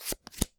card_flip.mp3